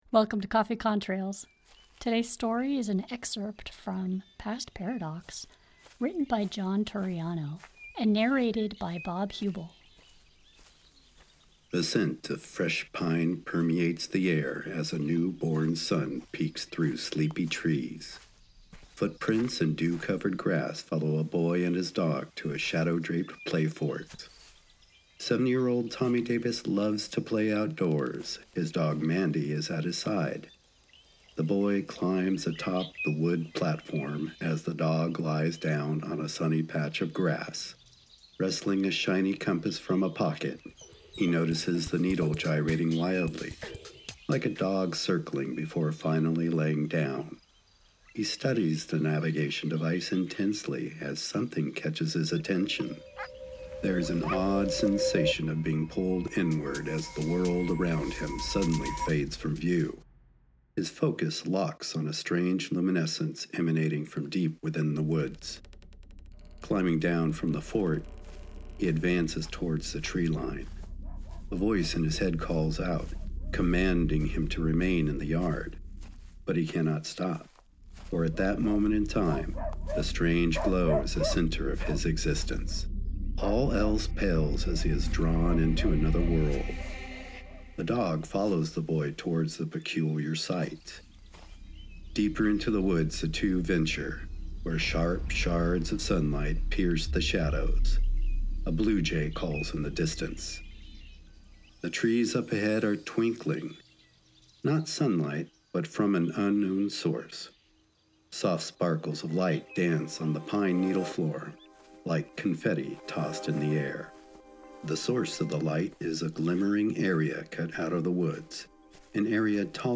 Coffee Contrails - radio plays & audiobooks